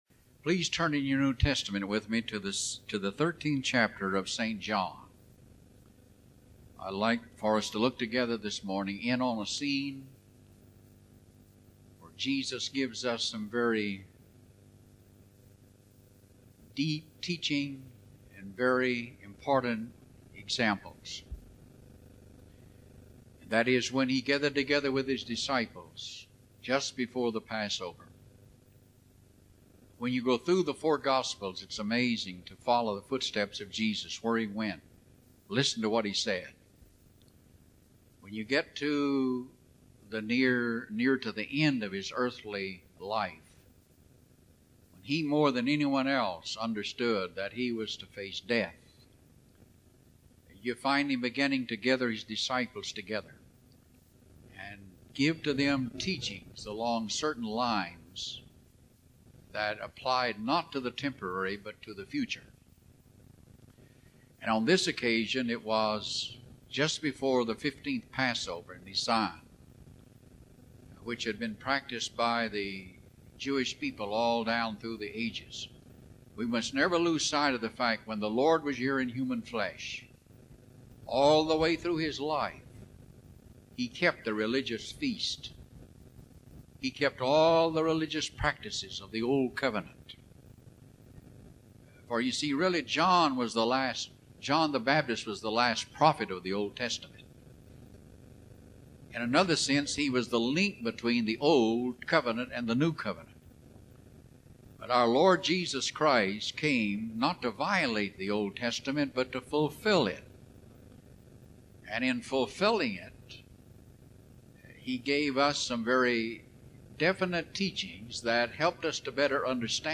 Sermons Need for a Foot Washing